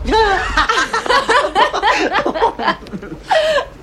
• MAN AND WOMAN LAUGH.wav
MAN_AND_WOMAN_LAUGH_0PL.wav